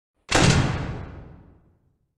Fnaf 1 Door